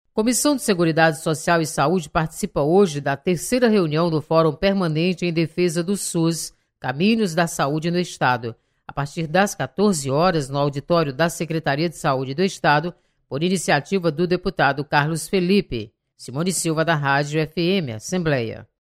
Comissão de Seguridade Social e Saúde participa de fórum em defesa do SUS. Repórter